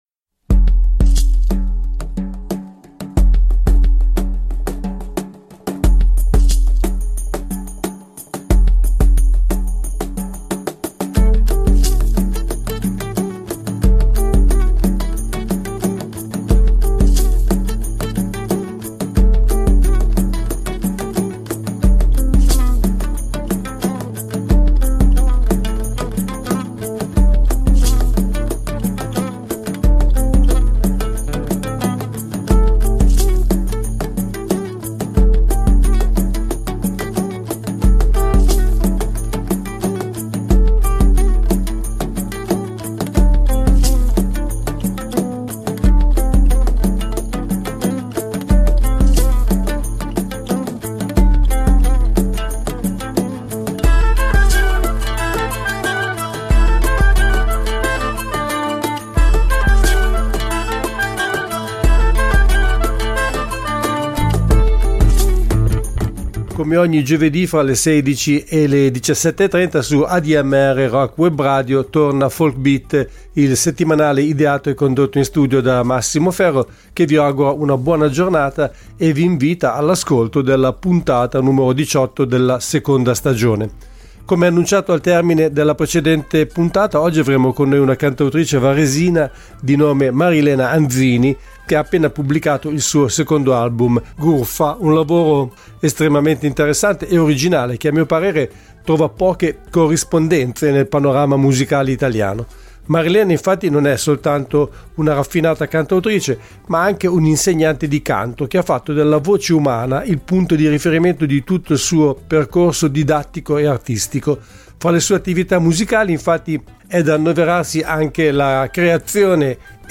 Ospite del programma al telefono